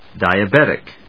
音節di・a・bet・ic 発音記号・読み方
/dὰɪəbéṭɪk(米国英語), ˌdaɪʌˈbetɪk(英国英語)/